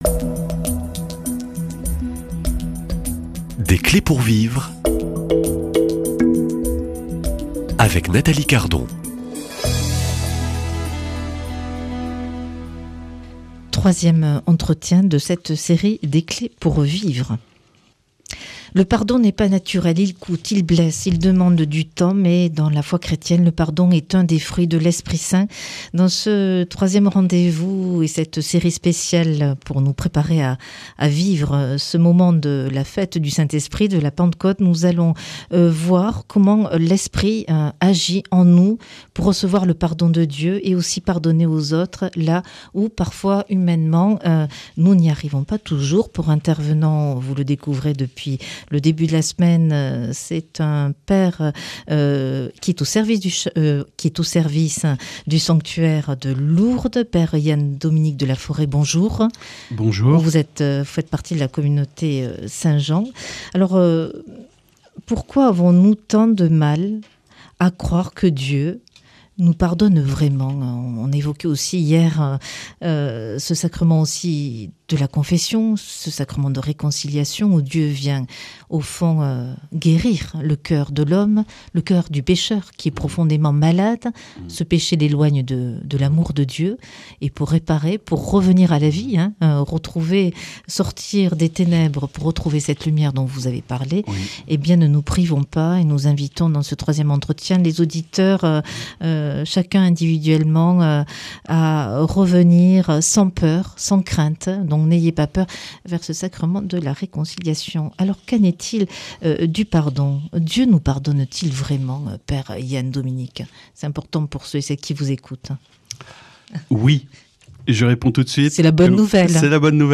Dans ce troisième entretien nous allons voir comment l’Esprit agit en nous pour recevoir le pardon de Dieu et aussi pardonner aux autres, là où, humainement, nous n’y arrivons pas.